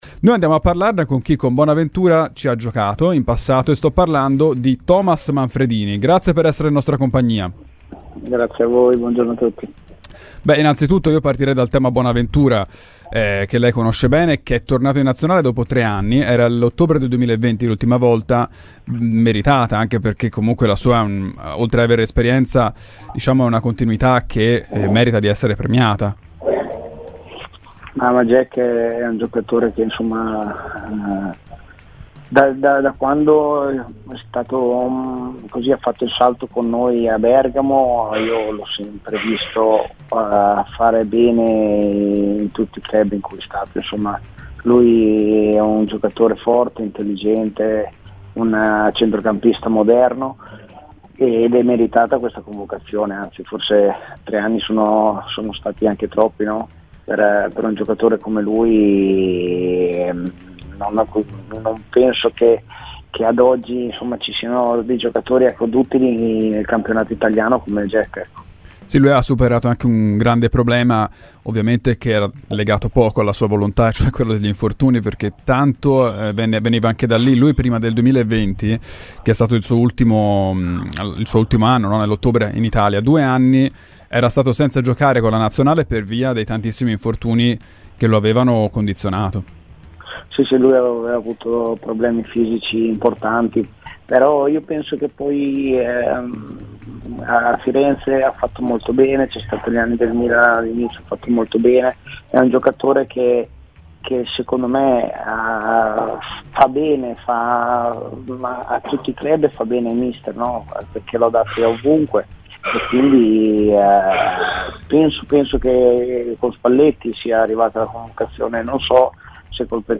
Intervenuto su Radio FirenzeViola, l'ex Fiorentina Thomas Manfredini ha detto la sua sul grande avvio dei viola, iniziando dall'ottimo stadio di forma dei centrali difensivi: "Avere tre centrali così forti è solo un vantaggio per la Fiorentina.